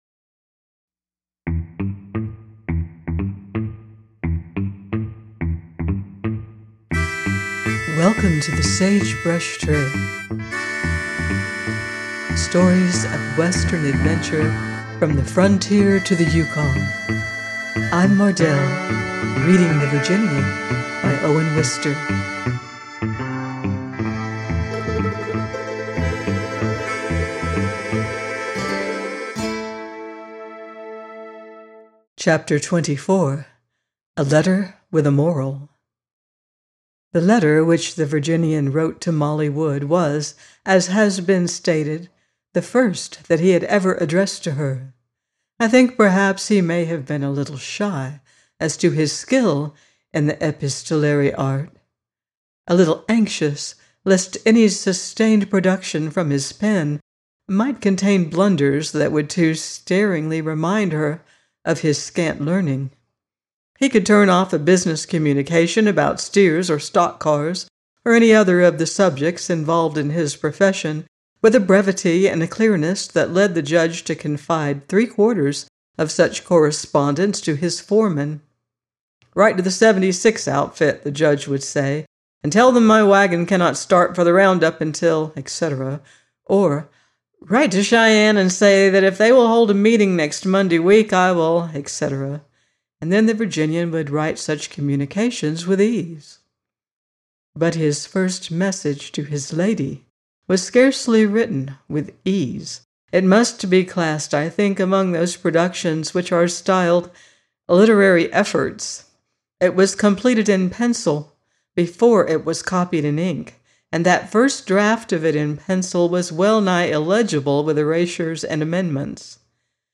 The Virginian 24 - by Owen Wister - audiobook